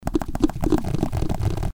Мультяшный звук ползающей гусеницы